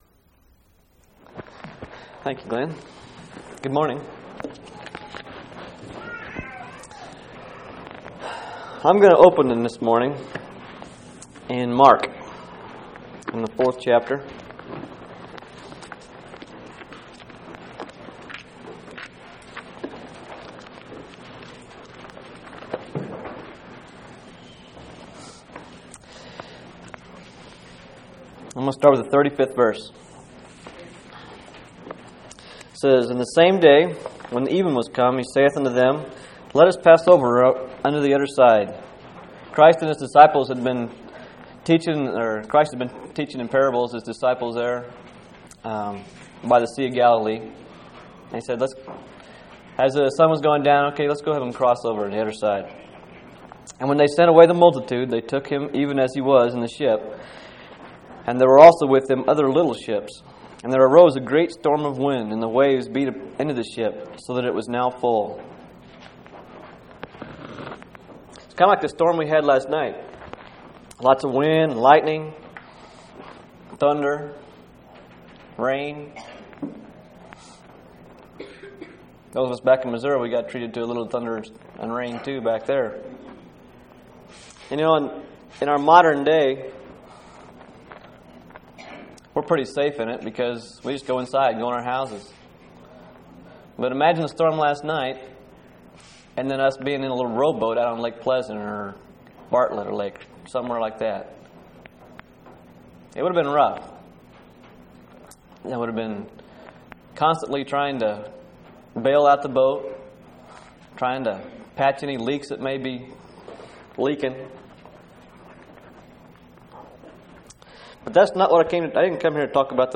8/16/1998 Location: Phoenix Local Event